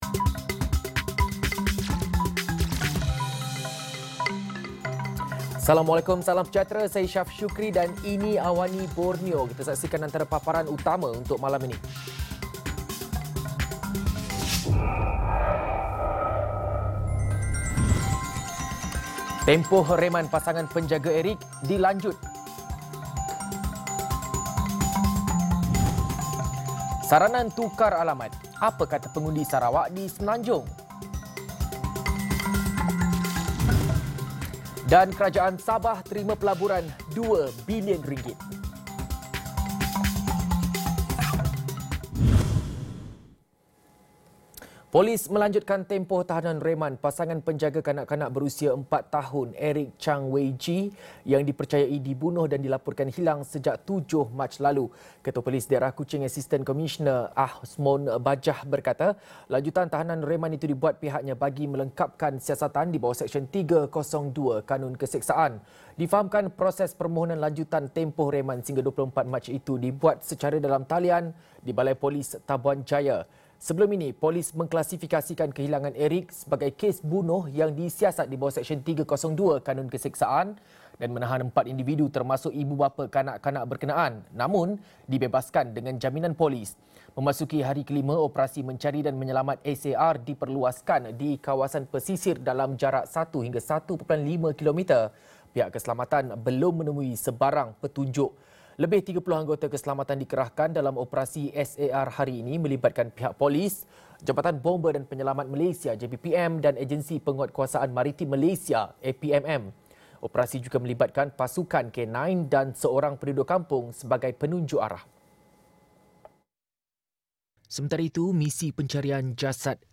Laporan berita padat dan ringkas dari Borneo